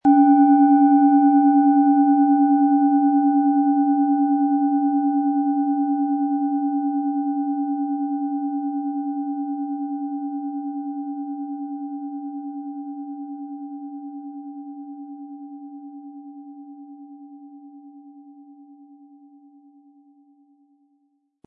Planetenton
Diese Klangschale stammt aus einem alten Meisterbetrieb und kleinen Manufaktur, die seit Generationen Klangschalen nach den alten Überlieferungen herstellt.
Um den Originalton der Schale anzuhören, gehen Sie bitte zu unserer Klangaufnahme unter dem Produktbild.
GrößeS
SchalenformBihar
MaterialBronze